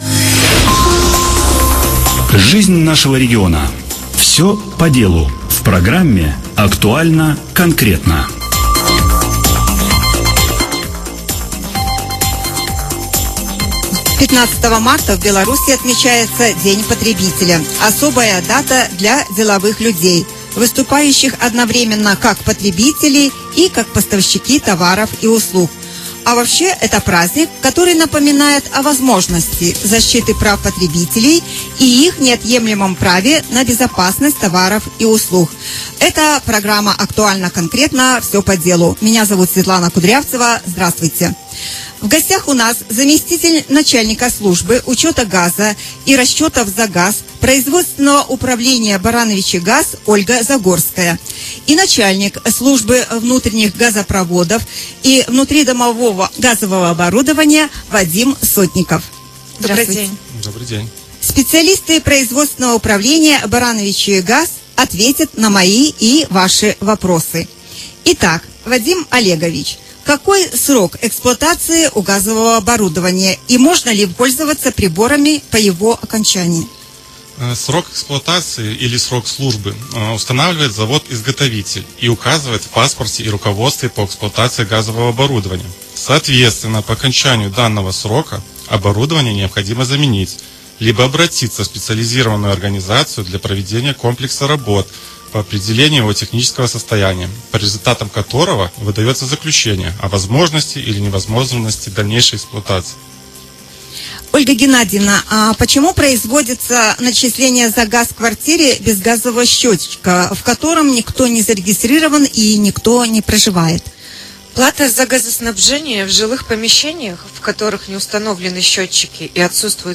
Специалисты производственного управления «Барановичигаз» ответили на вопросы слушателей.